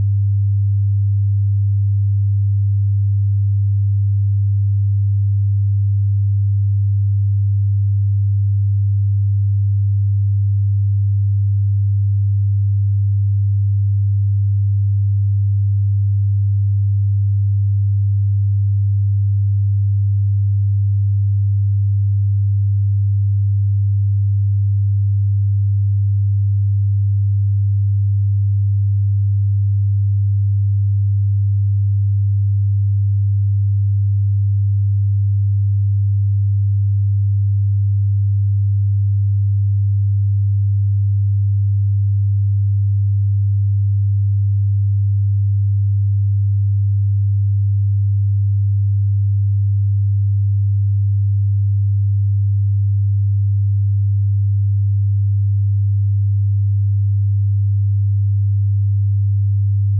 • Totalmente seguro: O nível de pressão sonora utilizado (80-85 dBZ, equivalente a 60,9-65,9 dBA) é comparável ao volume de uma conversa normal, bem abaixo dos limites estabelecidos pela Organização Mundial da Saúde
Clique aqui para baixar o arquivo de 100 Hz
Sound Spice é o nome atribuído à aplicação acústica de uma frequência sonora pura de 100 Hz, com objetivo de reduzir sintomas de enjoo de movimento.